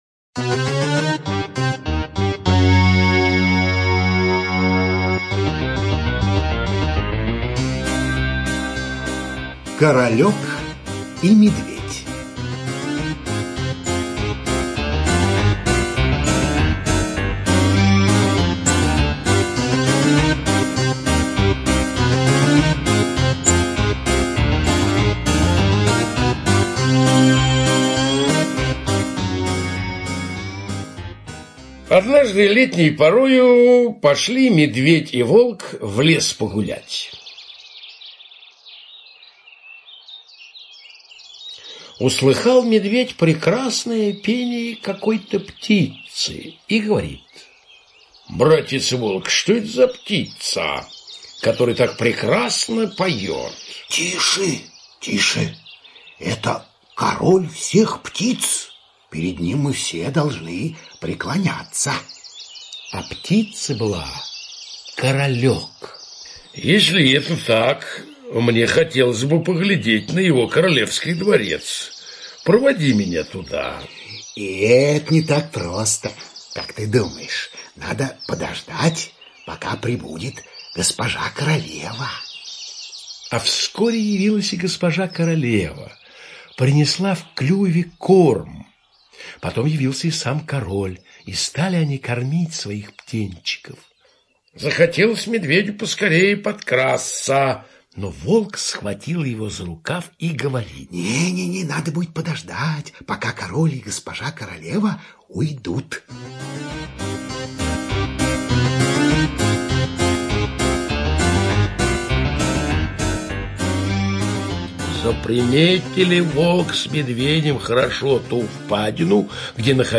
Королёк и медведь – Братья Гримм (аудиоверсия)